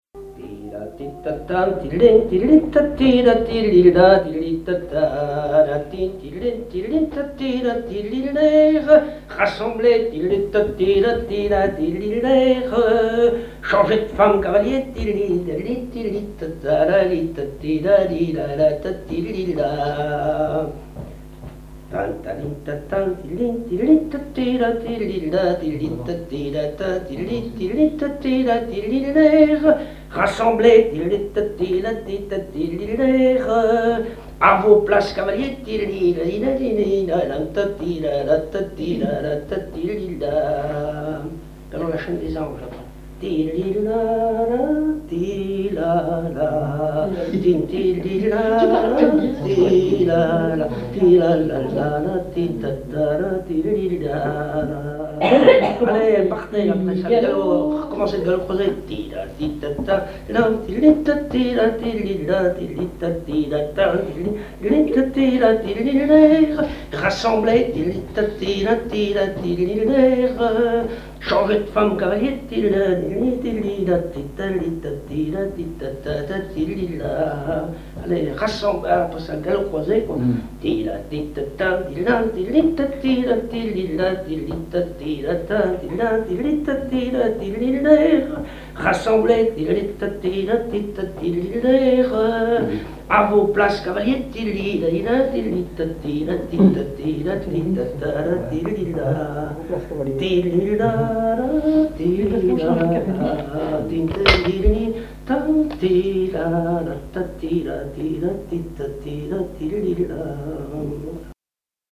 danse : quadrille
Pièce musicale inédite